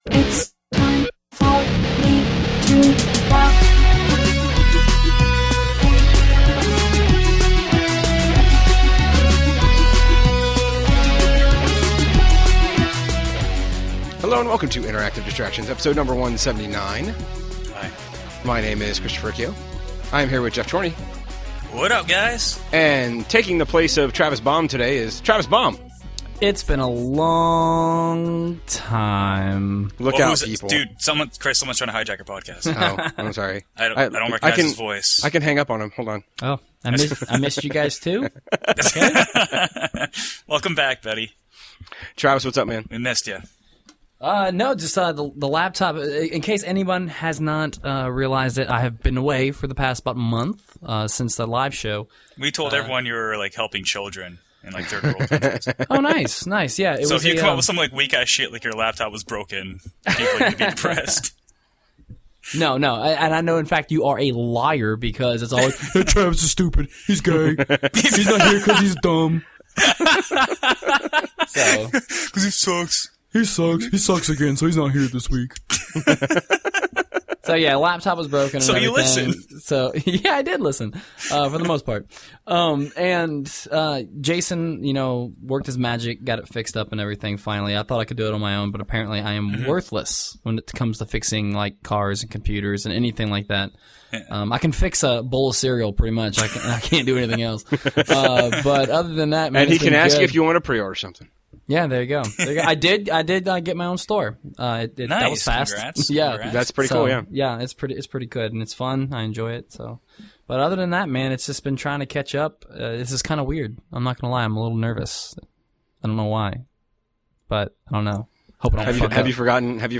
The main 3 are finally back in the studio just in time for the latest mailbag installment.